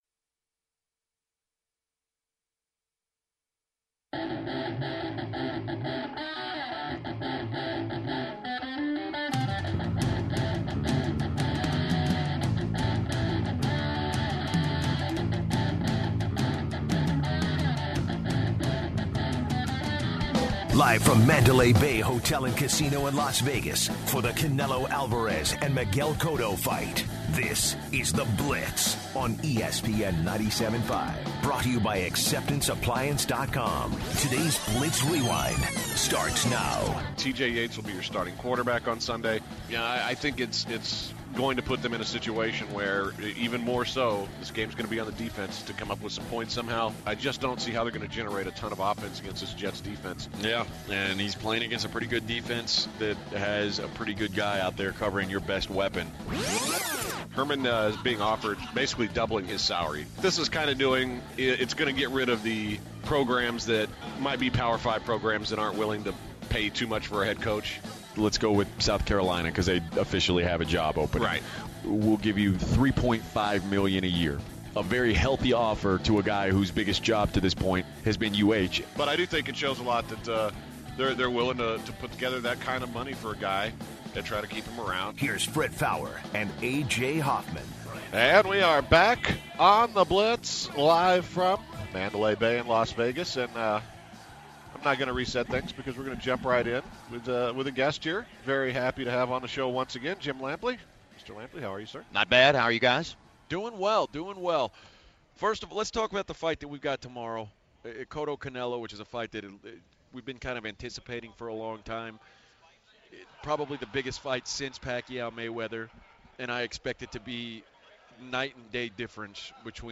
In the last hour of the show, HBO boxing commentator Jim Lampley joins the show to talk about the big fight on Saturday. The guys also discuss the percentage of some NFL teams that have a chance of making the playoffs. Undefeated boxing star Andre Ward talks to the guys about his thoughts on Cotto vs. Canelo and what to expect from him soon. At the end of the show, we hear the "stupid stuff" the guys have said this week.